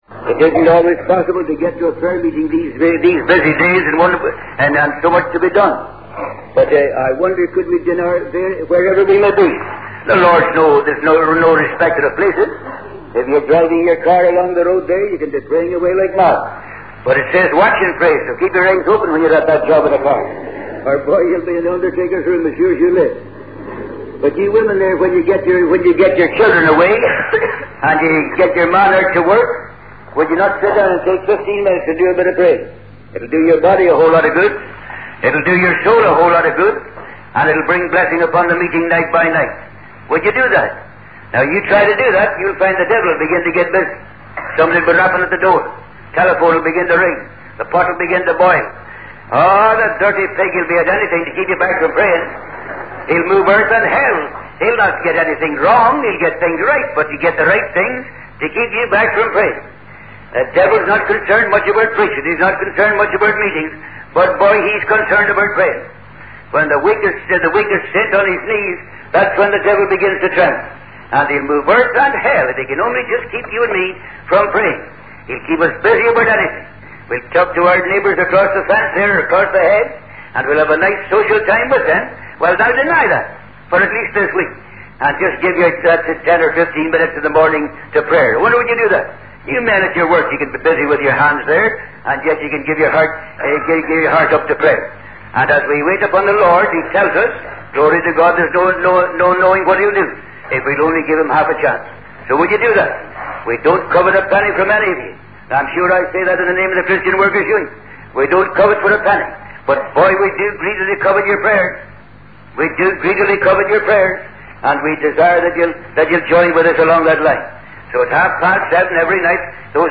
In this sermon, the speaker emphasizes the importance of prayer in the life of a believer.